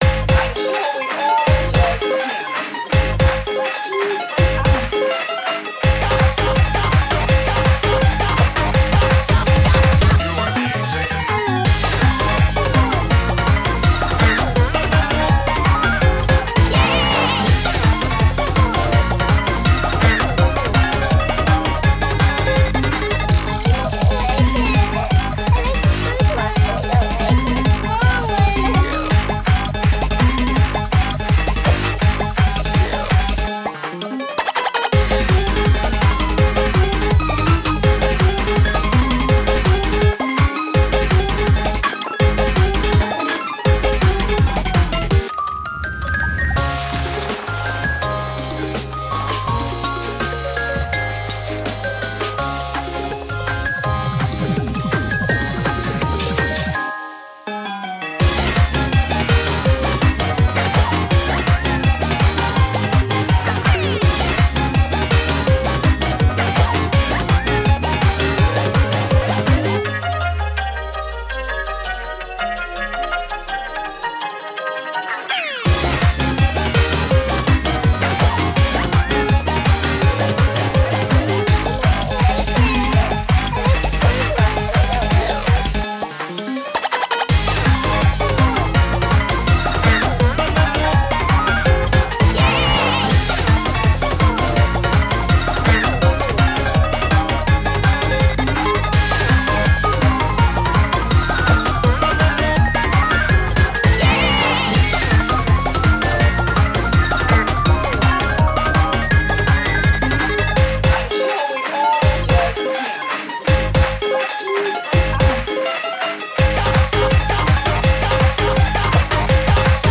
BPM165
MP3 QualityMusic Cut